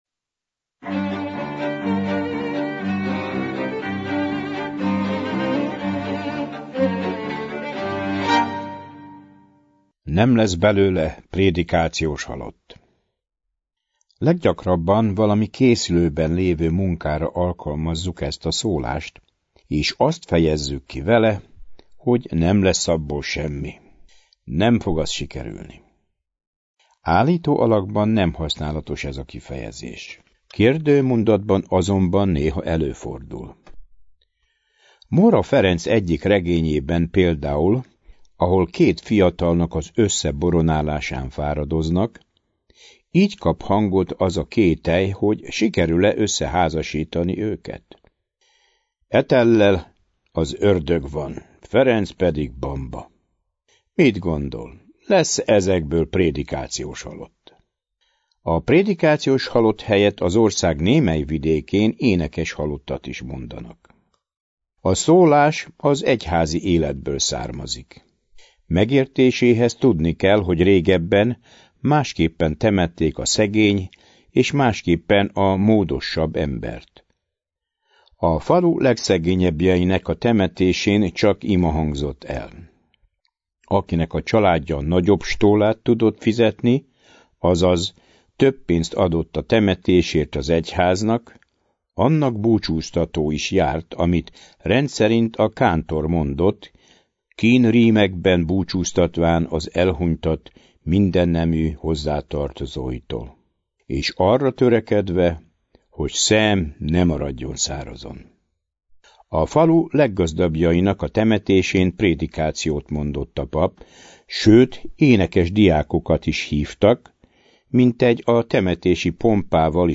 Műsorunk magyar szólásmondások rovatában mára egy igen érdekes de valóban igen csak gyéren használt szólásunk eredetét fogjuk elemezni. Hallgassuk meg mit mond  O. Nagy Gábor a “Nem lesz belőle prédikációs halott” című  szólásunkról a Mi fán terem című könyvében.